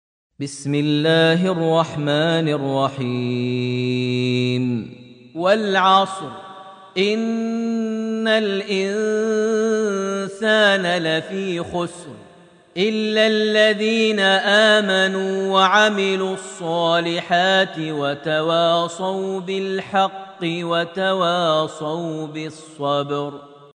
surat Al-Asr > Almushaf > Mushaf - Maher Almuaiqly Recitations